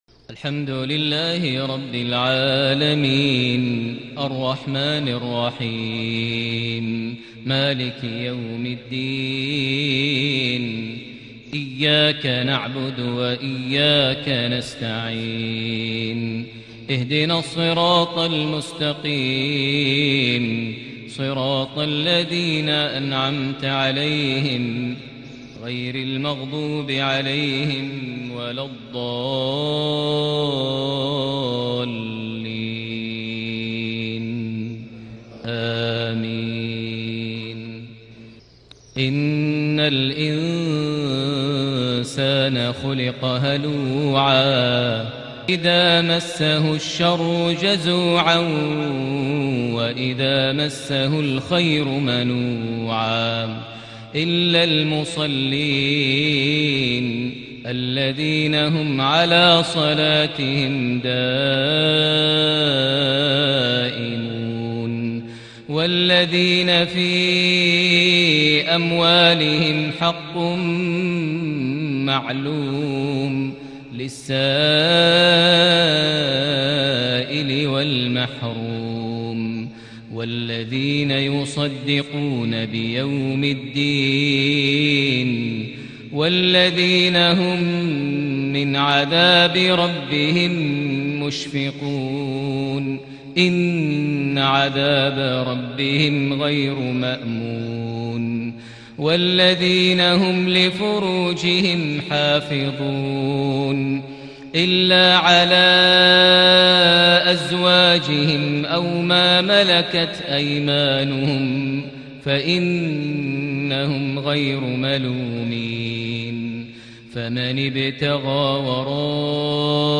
صلاة المغرب ٩ ربيع الأول ١٤٤١هـ سورة المعارج ١٩-٤٤ > 1441 هـ > الفروض - تلاوات ماهر المعيقلي